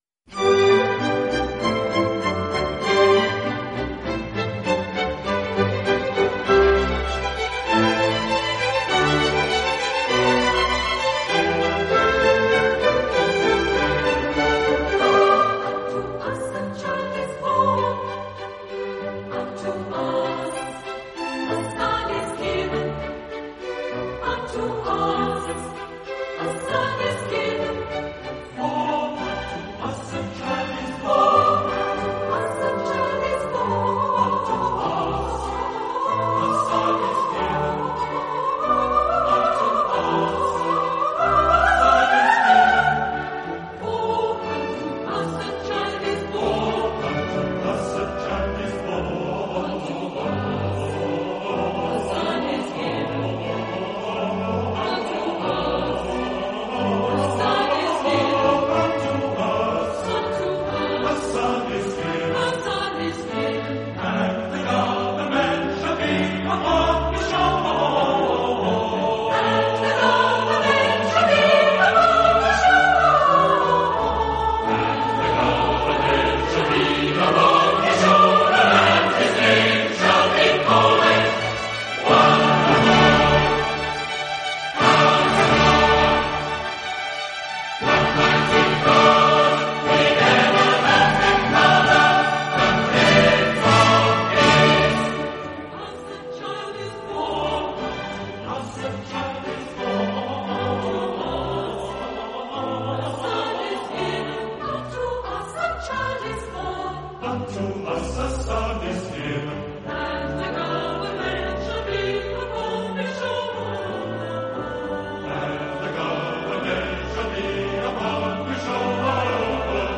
“For Unto Us a Child is Born” from the album Messiah. Performed by the London Philharmonic Orchestra and London Philharmonic Choir, composed by George Frideric Handel.